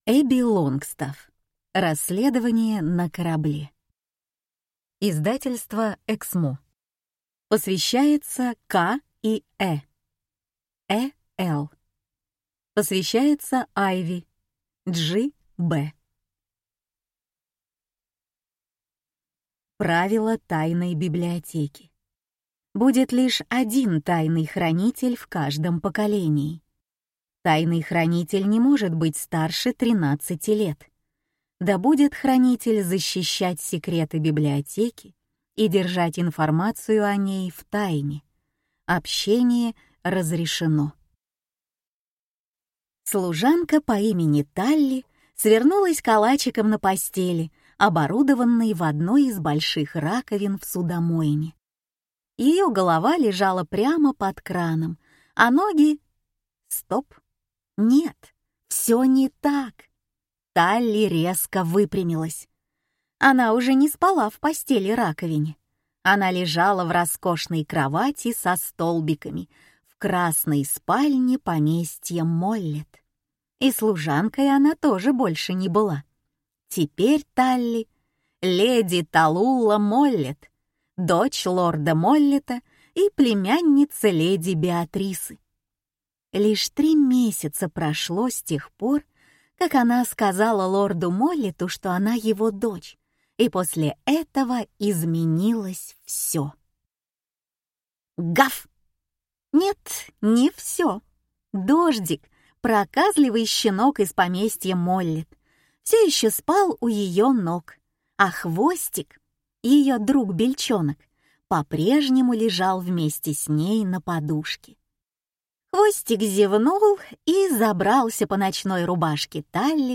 Аудиокнига Расследование на корабле | Библиотека аудиокниг